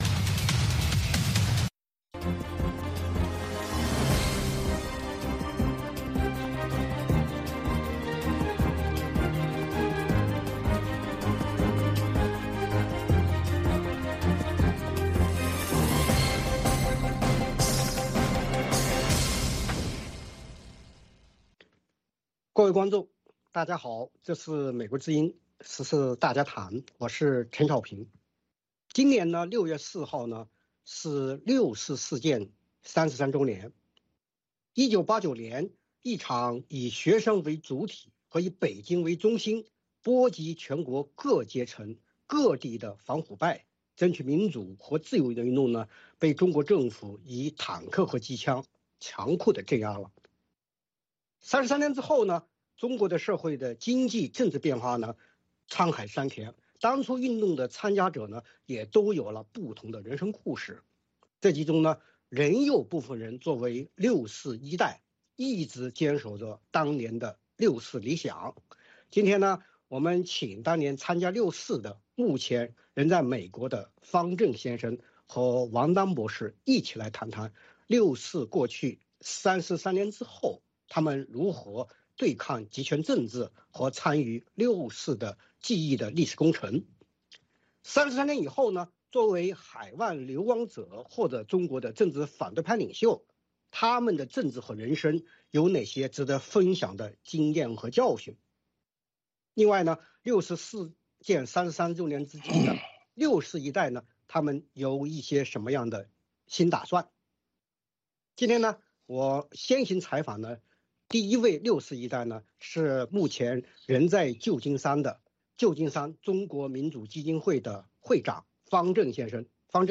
美国之音中文广播于北京时间晚上9点播出《VOA卫视》节目(电视、广播同步播出)。